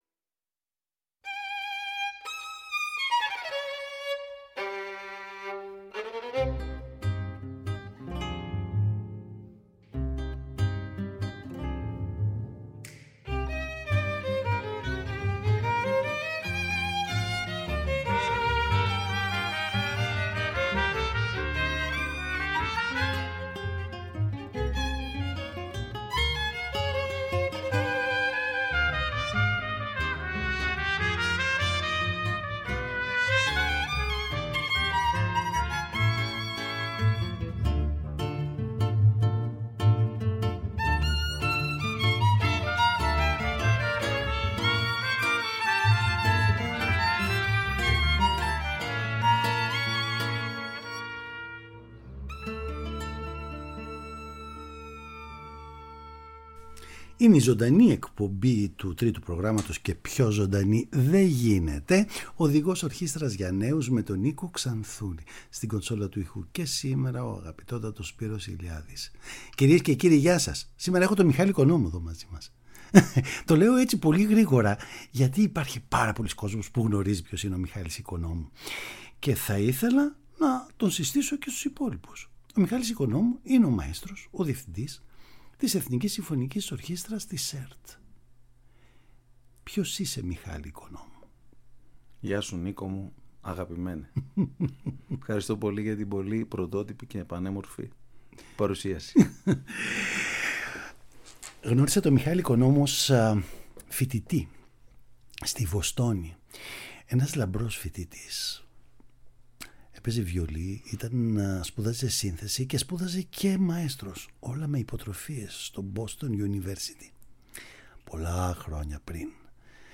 Παραγωγή-Παρουσίαση: Νίκος Ξανθούλης